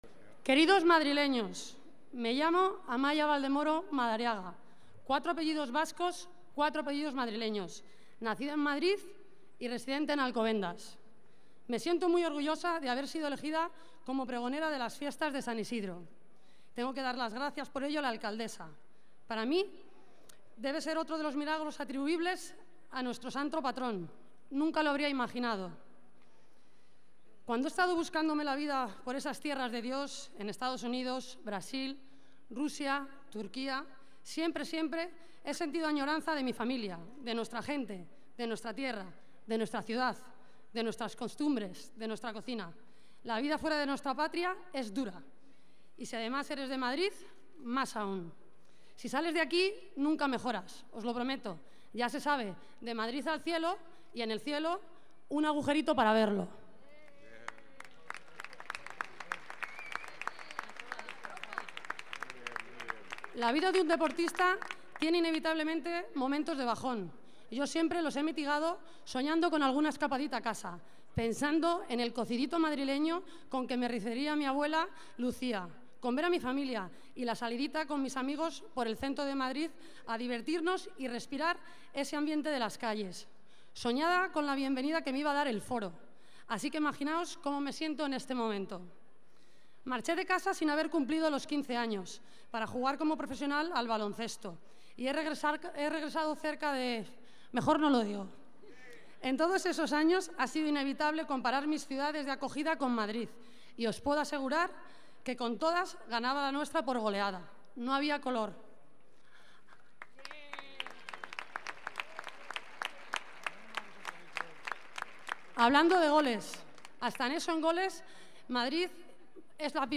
Nueva ventana:Amaya Valdemoro: lectura del pregón